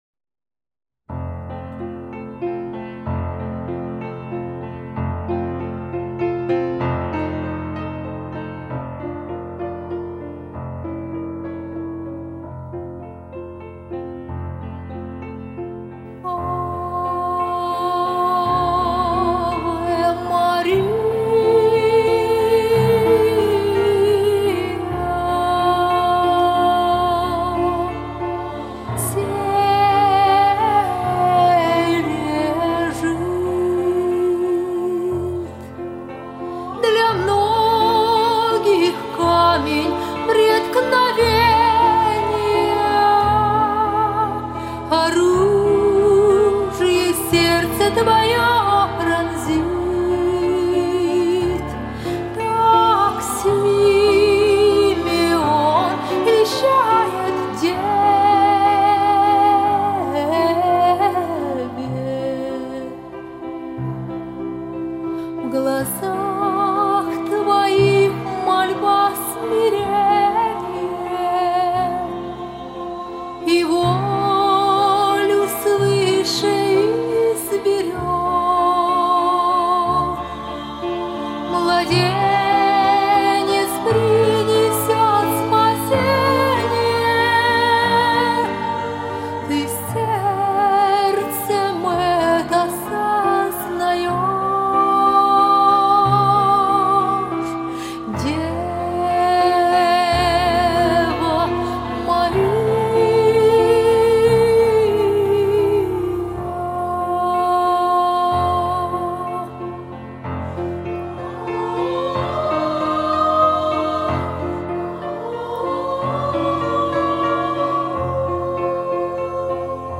Дева Мария (Ф. Шуберт) (соло и жен. хор)
Для сольного исполнения (возможно сопровождение женского хора)
Дева Мария (Ф. Шуберт) (соло и жен. хор) Для сольного исполнения (возможно сопровождение женского хора) Музыка: Франц Шуберт Переложение для женского хора: С. Хащук С сопровождением женского хора в Фа-Мажоре Оригинал в Си-Бемоль-Мажоре